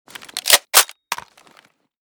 AR_unjam.ogg